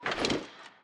equip_gold4.ogg